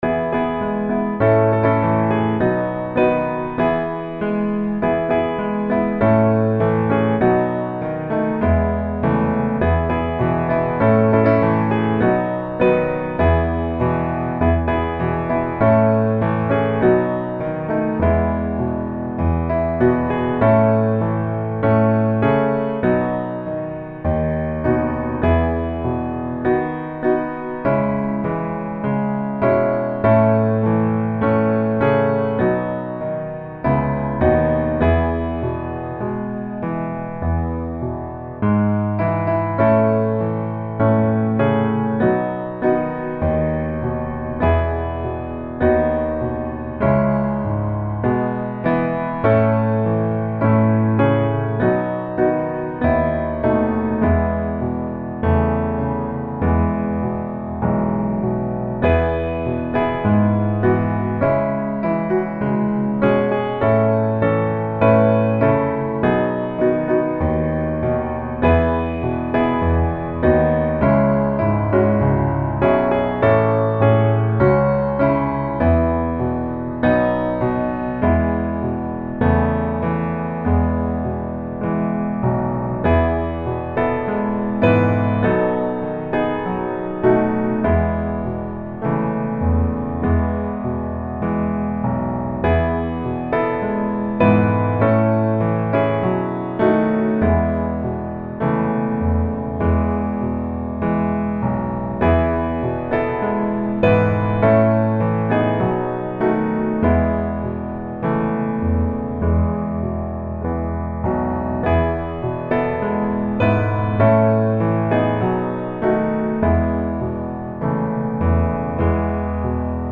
E Major